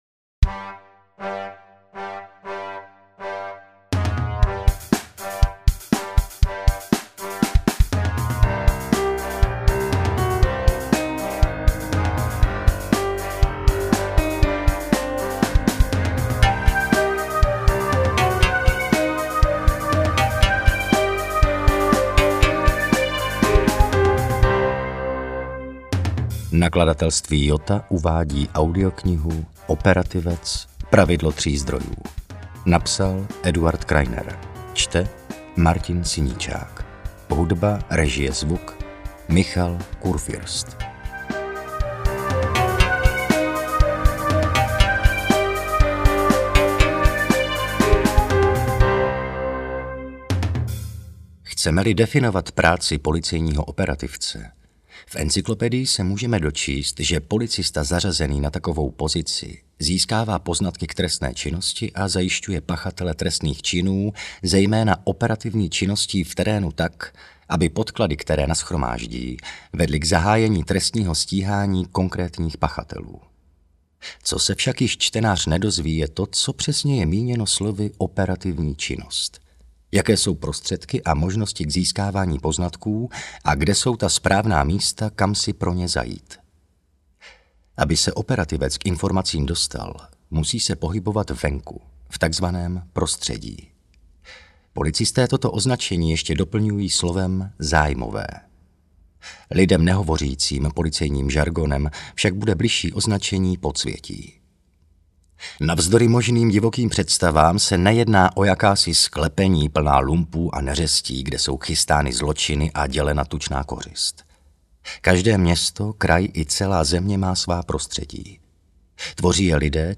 Operativec: Pravidlo tří zdrojů audiokniha
Ukázka z knihy